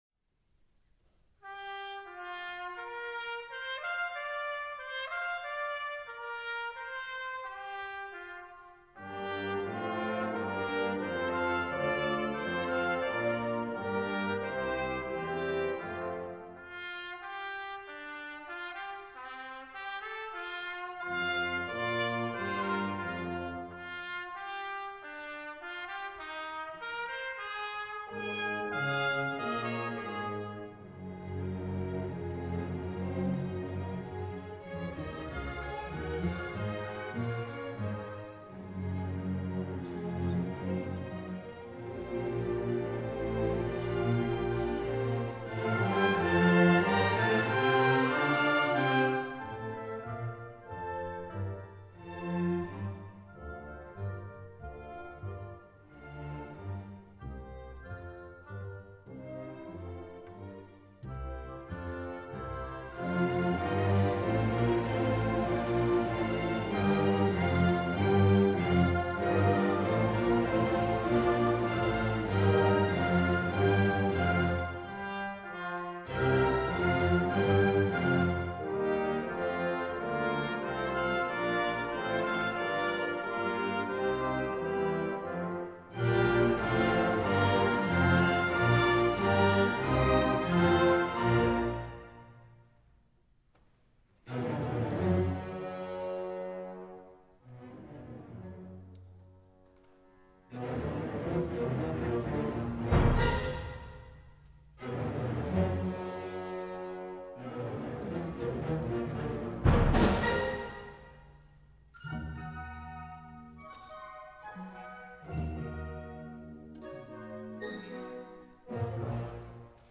Symphony Orchestra
National Concert Hall, Dublin. 3rd November 2015.